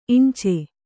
♪ iñce